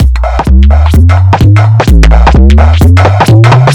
Index of /musicradar/uk-garage-samples/128bpm Lines n Loops/Beats
GA_BeatResC128-03.wav